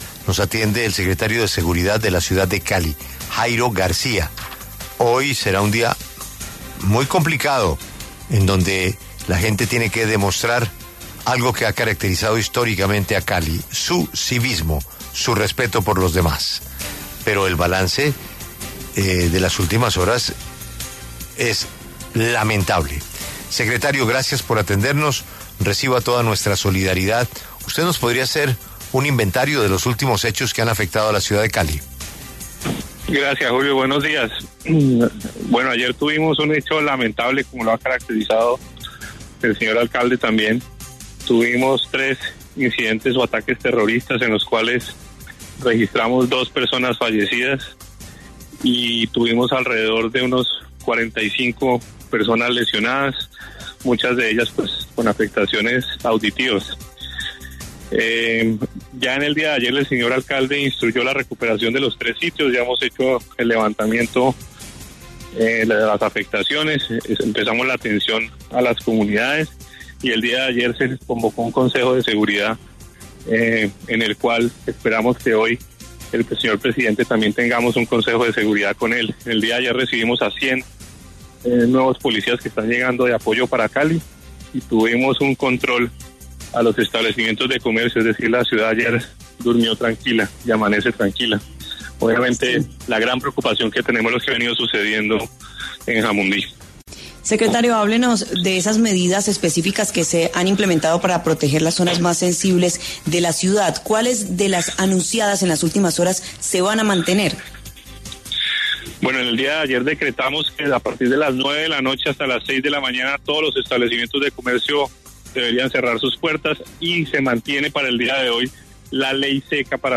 Así lo indicó el secretario de Seguridad de Cali, Jairo García, en entrevista con W Radio.